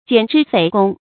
蹇之匪躬 jiǎn zhī fěi gōng
蹇之匪躬发音
成语注音ㄐㄧㄢˇ ㄓㄧ ㄈㄟˇ ㄍㄨㄙ